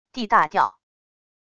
d大调wav音频